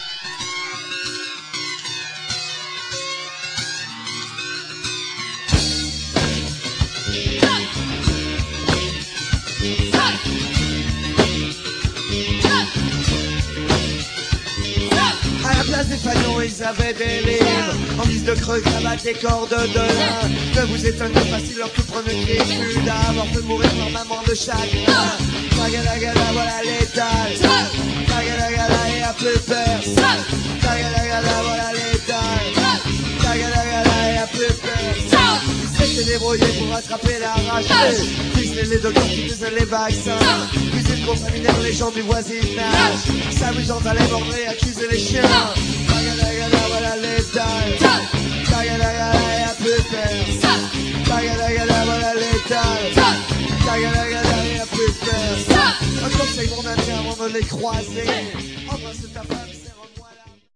Quand ils étaient petits, les Cow-Boys Etanches faisaient déjà du rock'n'roll !
guitare
basse
batterie